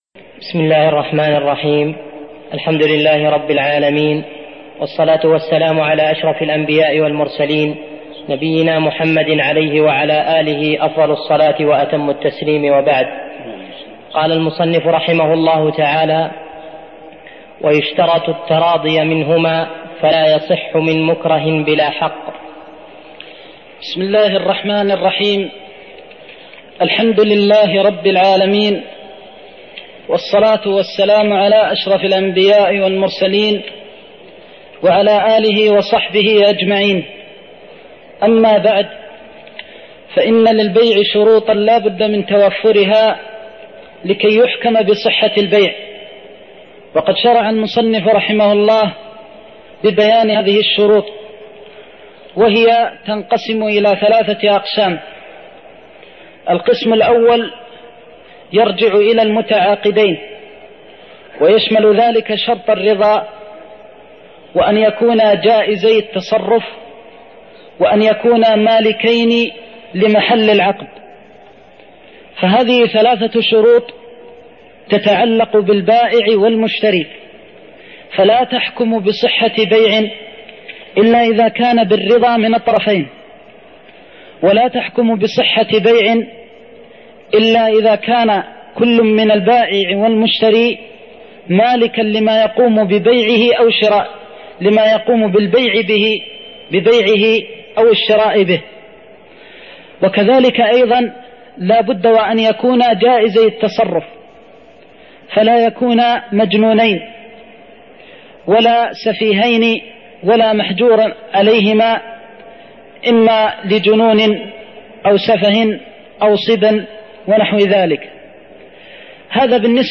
تاريخ النشر ٢١ صفر ١٤١٧ هـ المكان: المسجد النبوي الشيخ: فضيلة الشيخ د. محمد بن محمد المختار فضيلة الشيخ د. محمد بن محمد المختار قوله: ويشترط التراضي منهما (02)